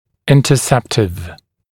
[ˌɪntə’septɪv][ˌинтэ’сэптив]профилактический